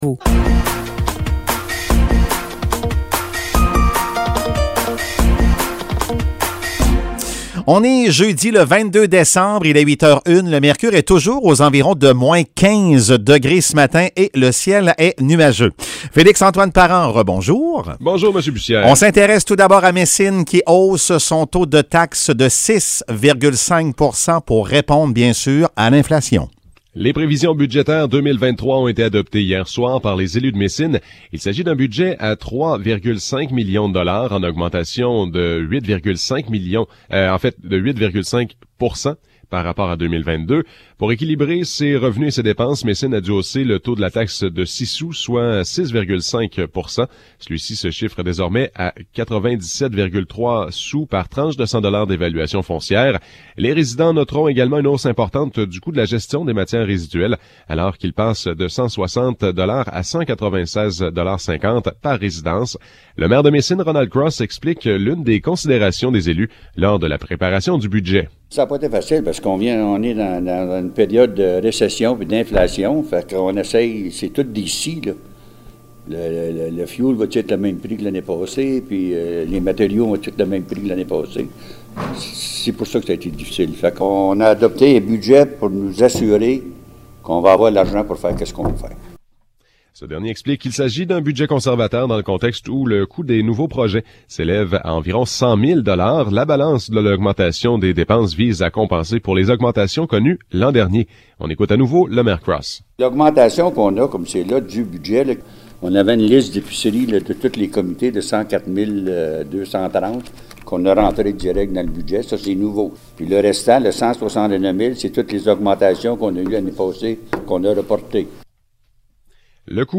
Nouvelles locales - 22 décembre 2022 - 8 h